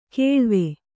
♪ kēḷve